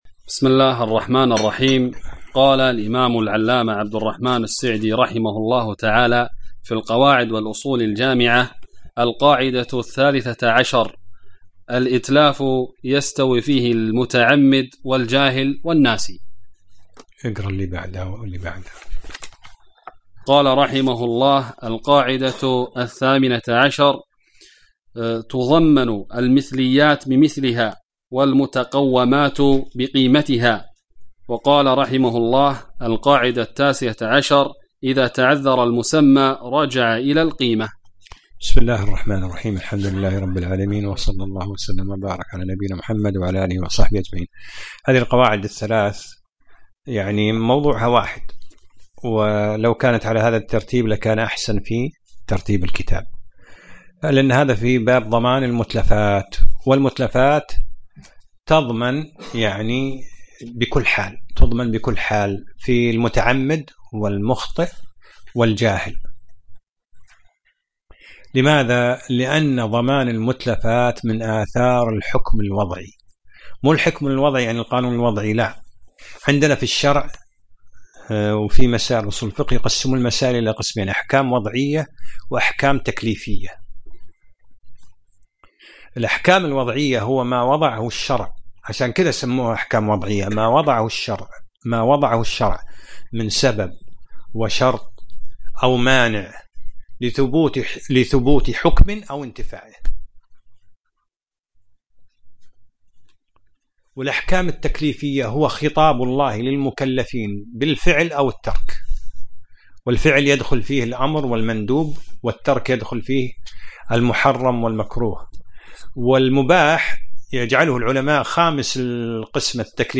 الدرس الثالث : من القاعدة 13